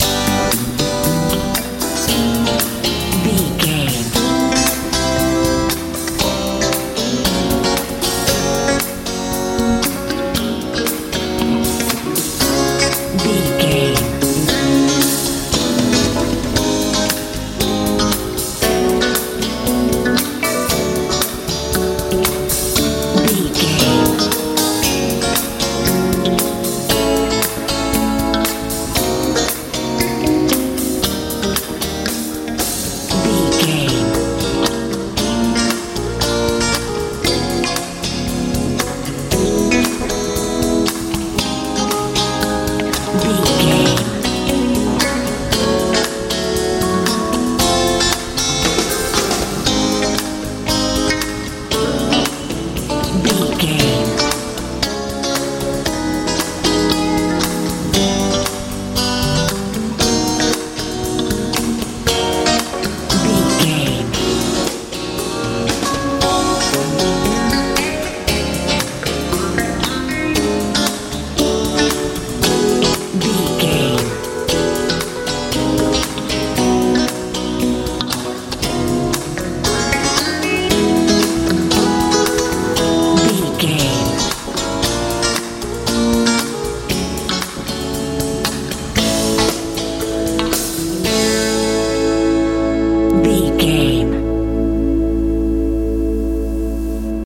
funk feel
Ionian/Major
hopeful
joyful
electric guitar
piano
bass guitar
drums
soft
soothing
smooth